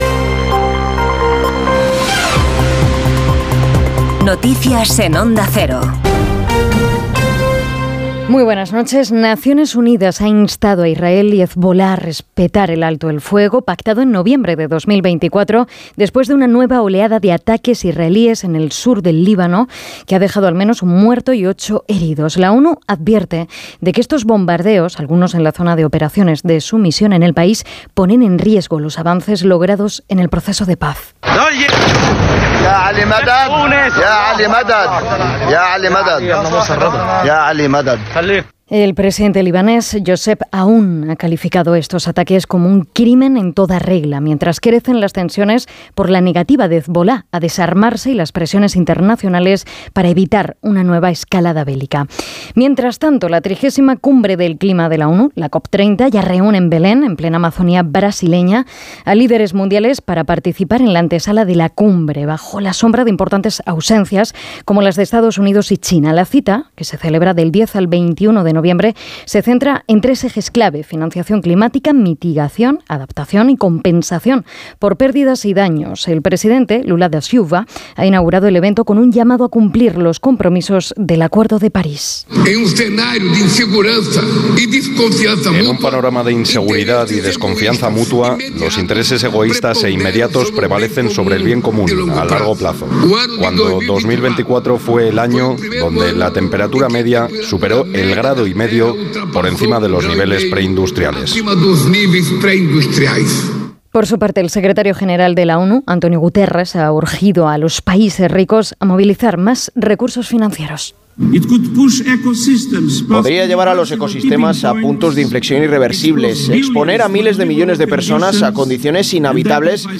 Conoce la ultima hora y toda la actualidad del dia en los boletines informativos de Onda Cero. Escucha hora a hora las noticias de hoy en Espana y el mundo y mantente al dia con la informacion deportiva.